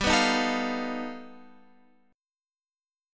G Augmented 9th